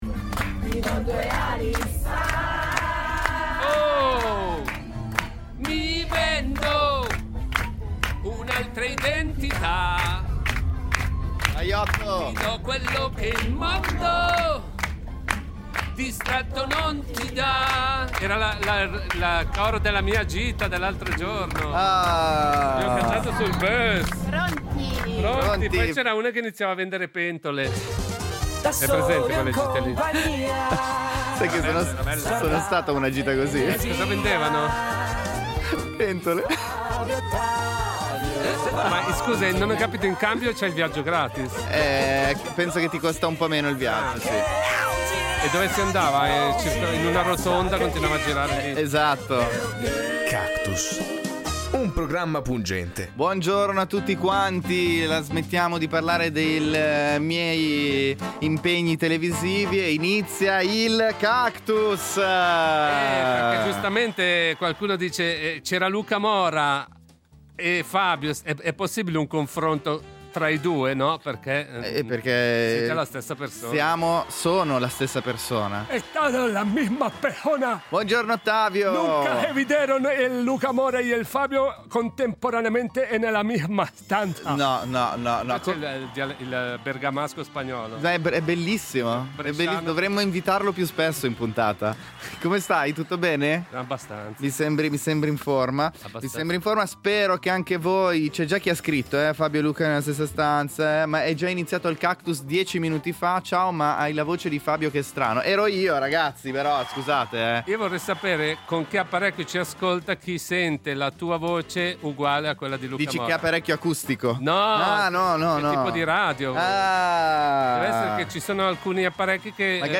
Gli ascoltatori leggono in “notiziarese” un evento della loro giornata come fosse una notizia. C’è chi festeggia il mesiversario, chi il complemese e chi non conosce neanche la data del suo anniversario. Avete mai, per sbadataggine, “prelevato” qualcosa da bar, ristoranti, hotel?